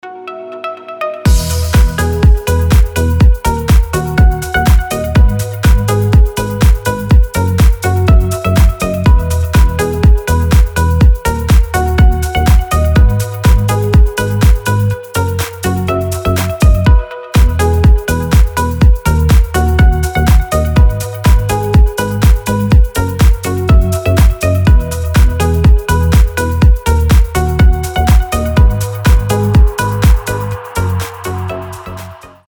• Качество: 320, Stereo
deep house
без слов
красивая мелодия